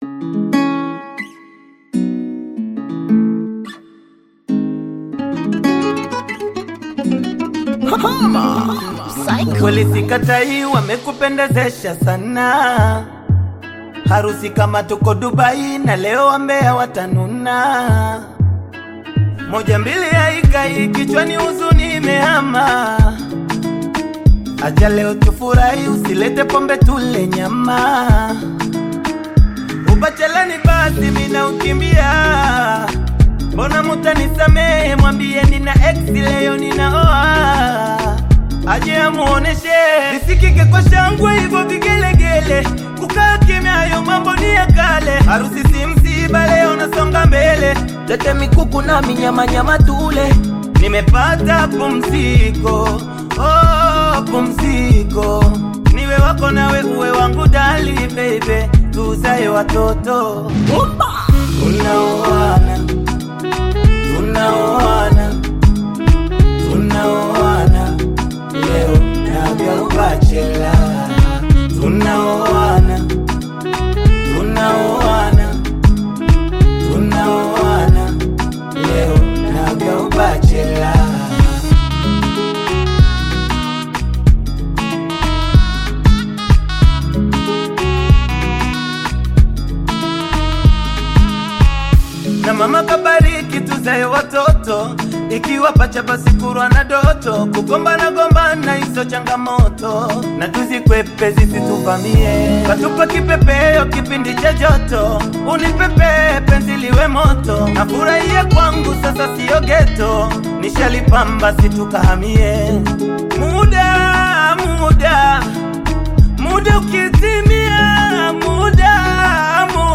Bongo Flava music track
Tanzanian Veteran Bongo Flava artist, singer and songwriter